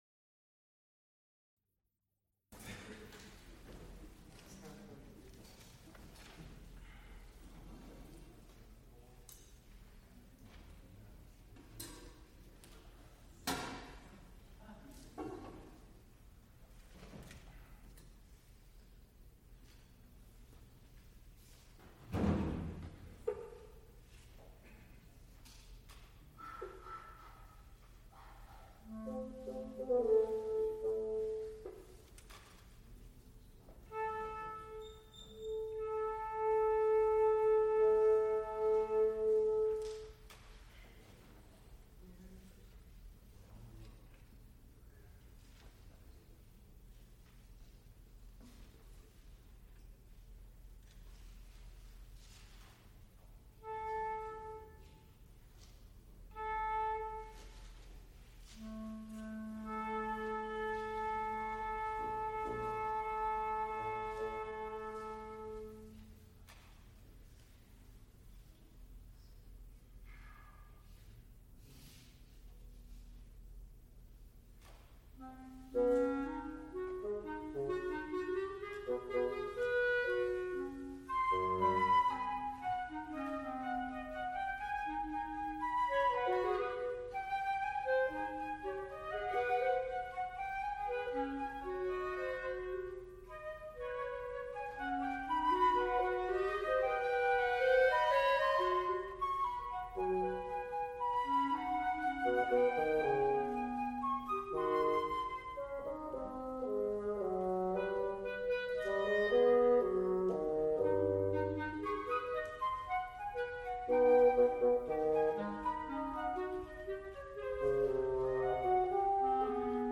Woodwind trios, Song cycles, Piano with instrumental ensemble, Clarinet and piano music, Songs
contralto
baritone
Recorded live March 26, 1980, Frick Fine Arts Auditoruium, University of Pittsburgh.
Music Department, University of Pittsburgh (depositor) Date 1980, 1980-03-28, [1980] Type Sound, musical performances Format sound recording, sound-tape reel, audio, audiotape reel, 2 audiotape reels : analog, quarter track, 7 1/2 ips ; 12 in.